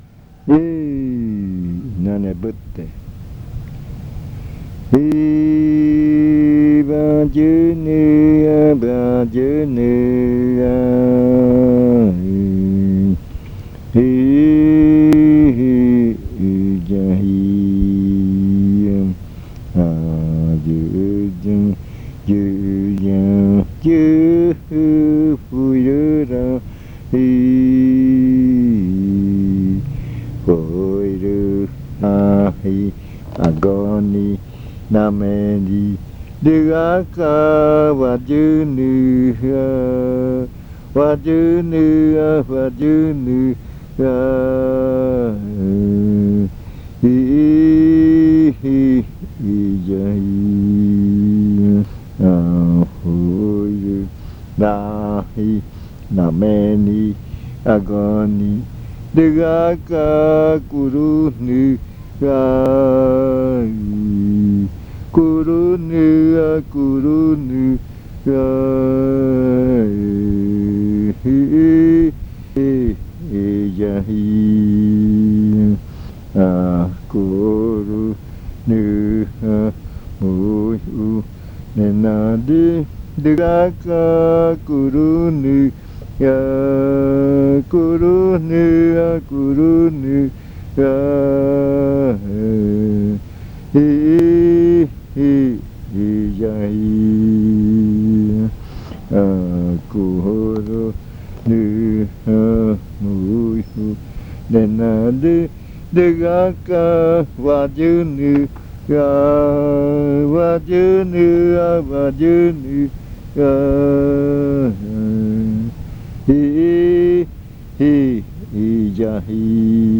Este canto hace parte de la colección de cantos del ritual Yuakɨ Murui-Muina (ritual de frutas) del pueblo Murui
Cantos de yuakɨ